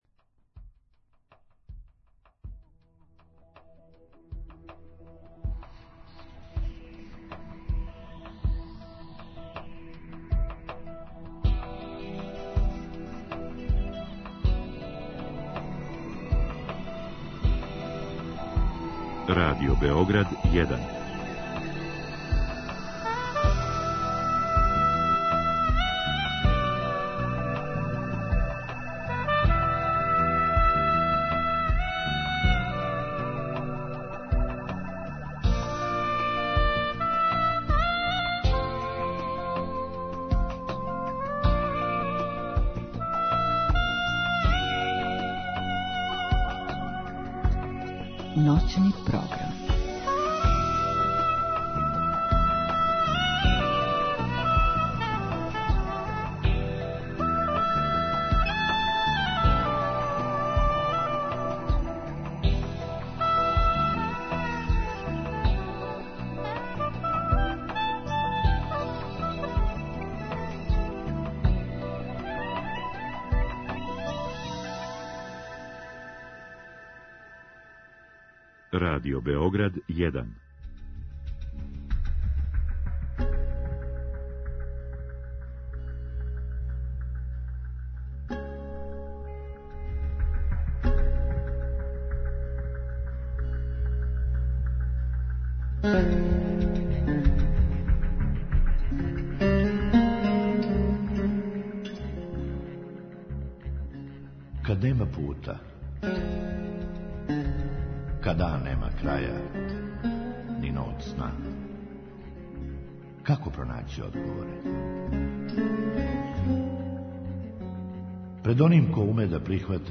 Други сат намењен је слушаоцима који у програму могу поставити питања нашем госту.